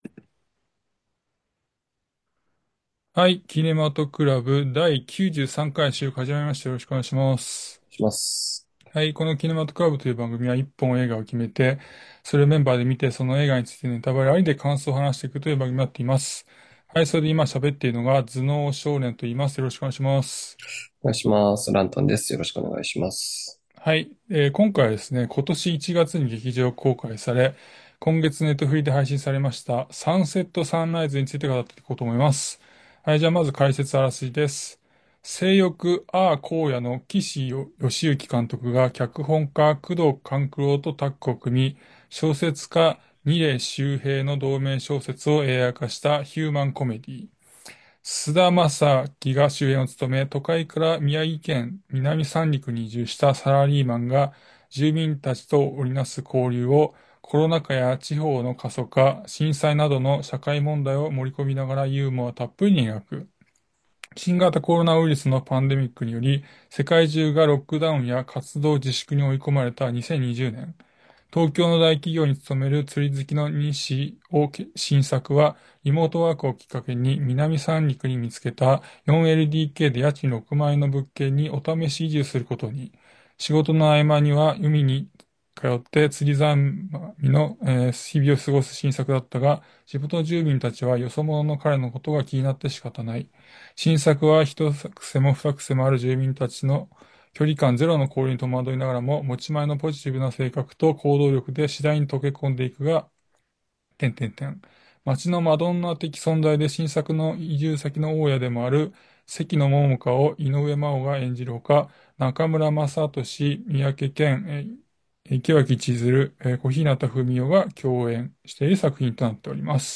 映画好きの男達が毎回映画の課題作を決め、それを鑑賞後感想を話し合います。